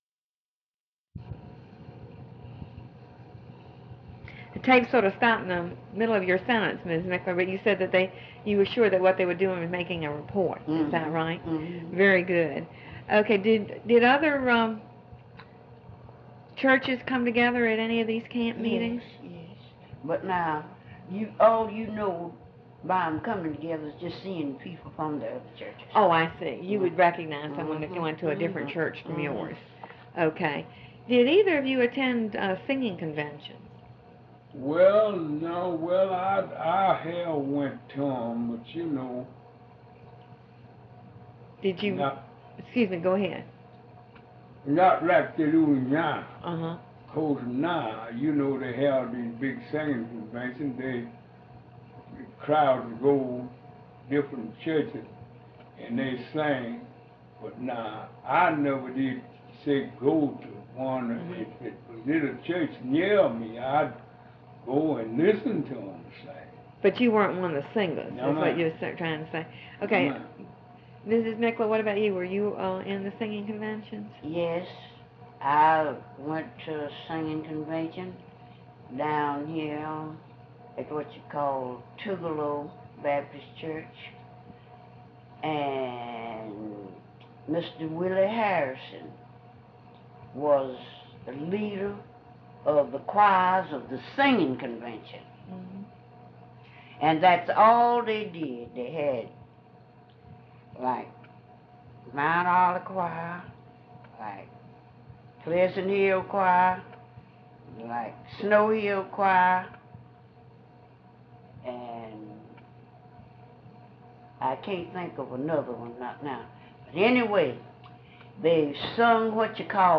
Part of Interview